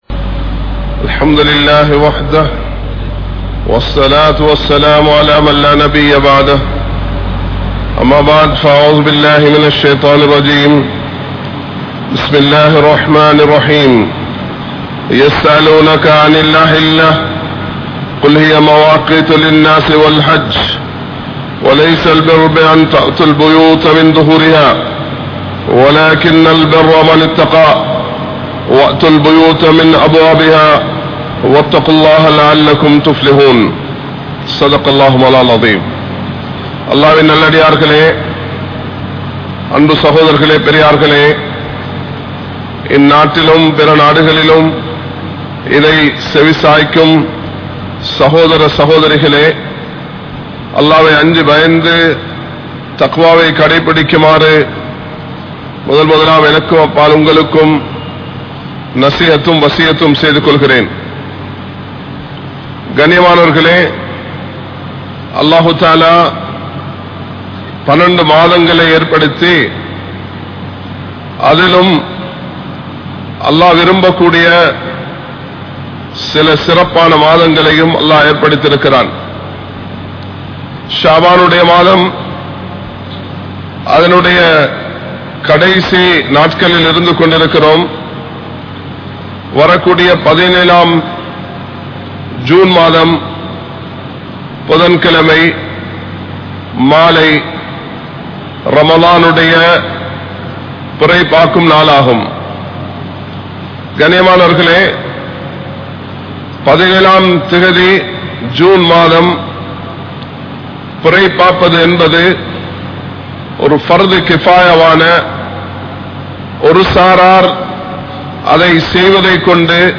Pirai Pattriya Thelivu (பிறை பற்றிய தெளிவு) | Audio Bayans | All Ceylon Muslim Youth Community | Addalaichenai
Colombo 03, Kollupitty Jumua Masjith